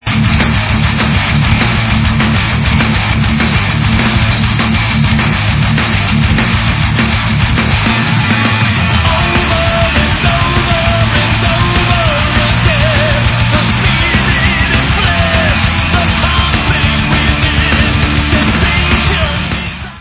Heavy Metal